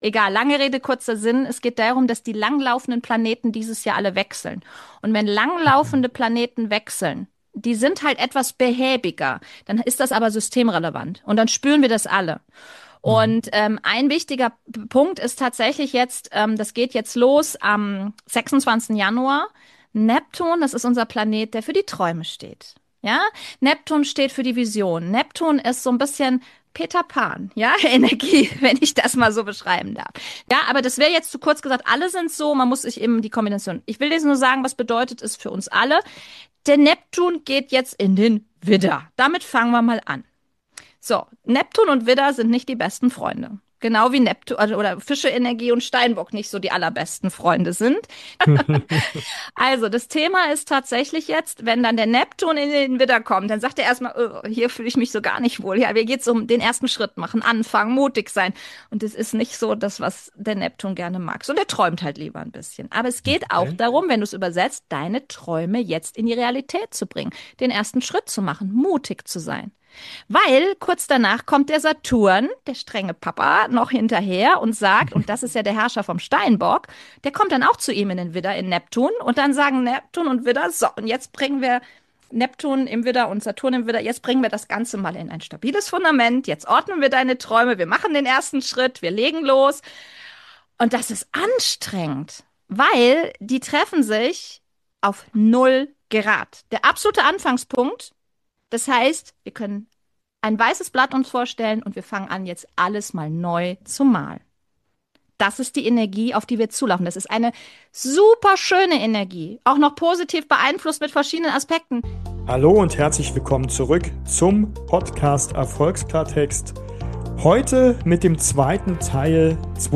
𝗜𝗻 𝗱𝗶𝗲𝘀𝗲𝗺 𝗸𝗿𝗮𝗳𝘁𝘃𝗼𝗹𝗹𝗲𝗻 𝗚𝗲𝘀𝗽𝗿ä𝗰𝗵 𝗲𝗿𝗳ä𝗵𝗿𝘀𝘁 𝗱𝘂: